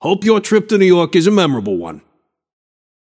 Shopkeeper voice line - Hope your trip to New York is a memorable one.